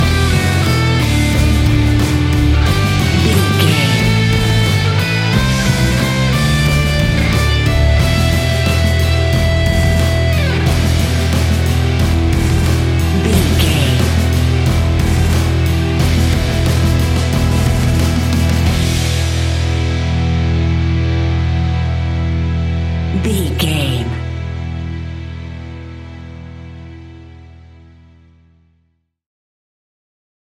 Epic / Action
Fast paced
Aeolian/Minor
hard rock
instrumentals
royalty free rock music
Heavy Metal Guitars
Metal Drums
Heavy Bass Guitars